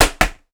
Helmet Impact Intense Tackle.wav